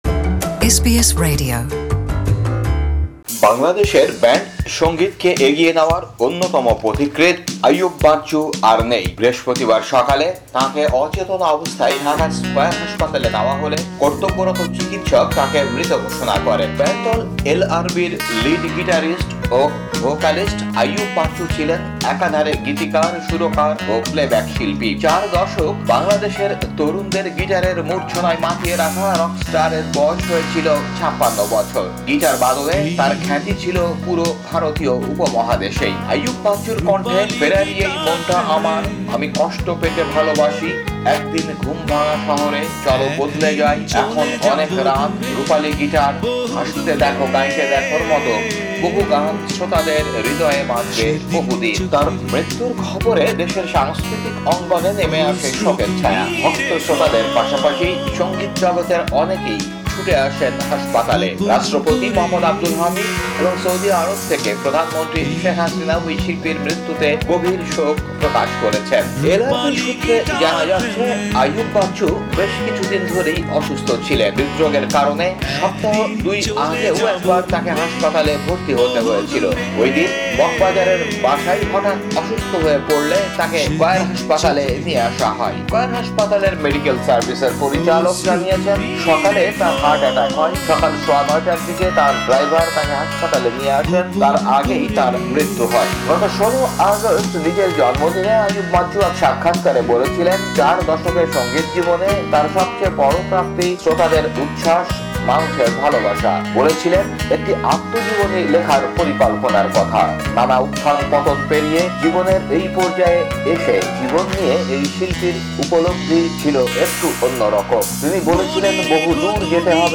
না ফেরার দেশে চলে যাওয়া আইয়ুব বাচ্চুকে নিয়ে একটি প্রতিবেদন